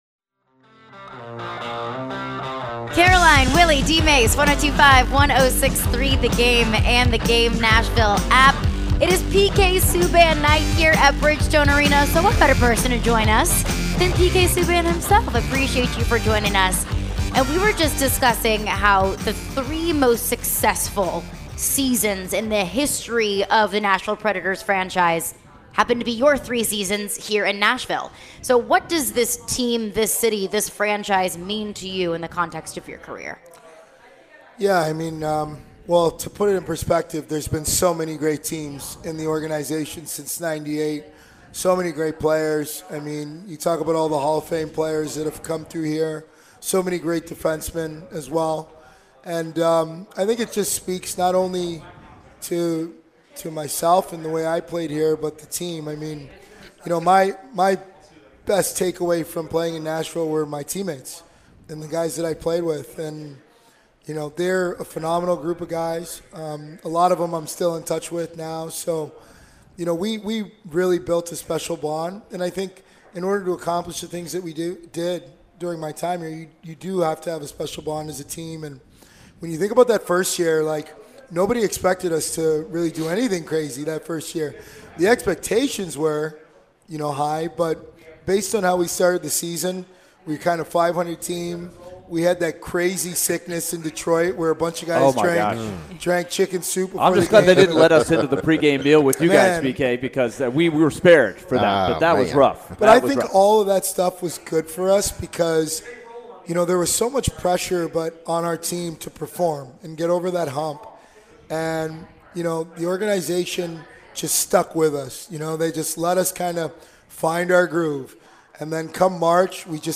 PK Subban Interview (2-13-23)